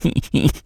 pgs/Assets/Audio/Animal_Impersonations/rabbit_squeak_03.wav at master
rabbit_squeak_03.wav